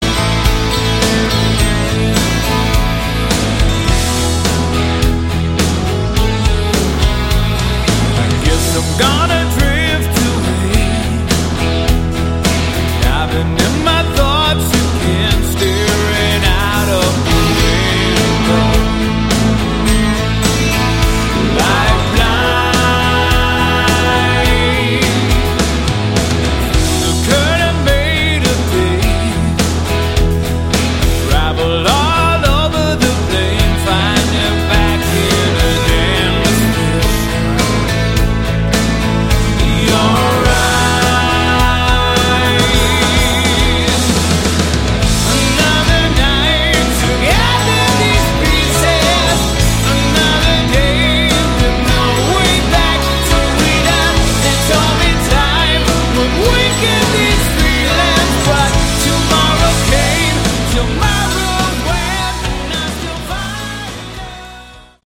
Category: AOR/Melodic Rock
It's simply good, straight ahead rock.